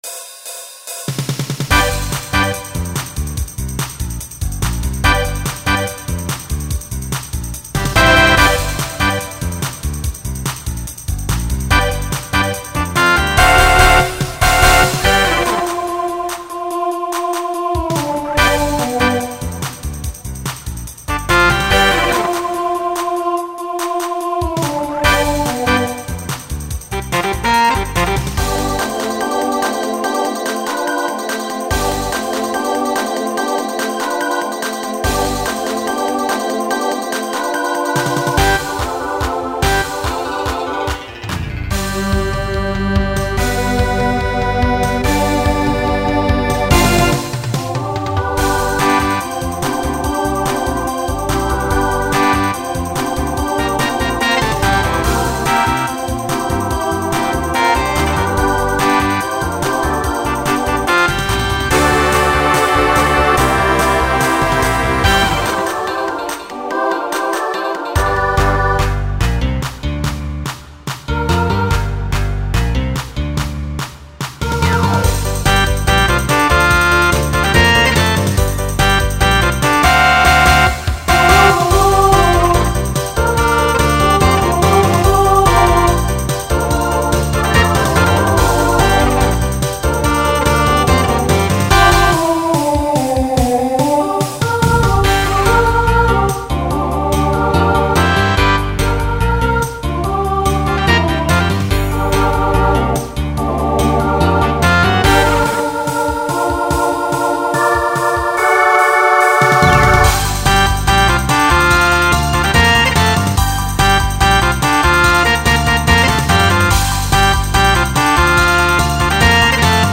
Pop/Dance Instrumental combo
Voicing SSA